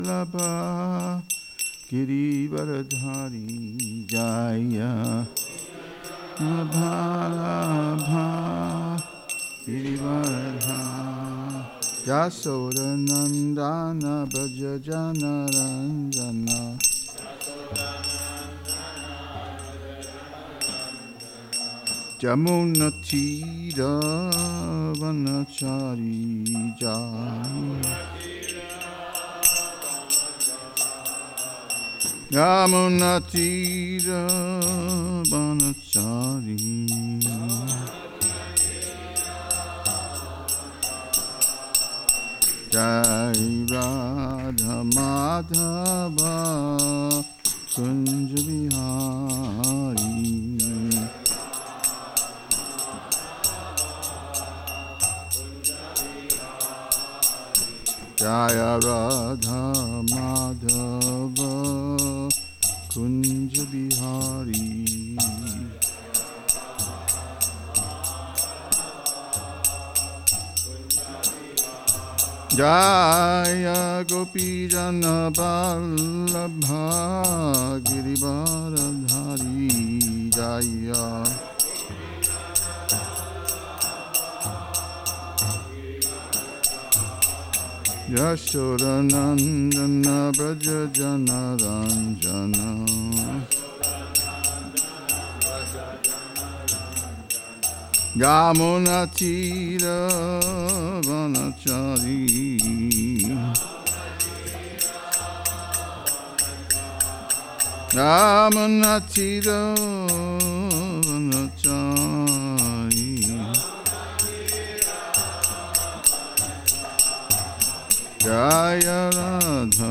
2024 at the Hare Krishna temple in Alachua, Florida. The Srimad-Bhagavatam is a sacred Vaishnava text from India that narrates the history of God (Krishna) and His devotees.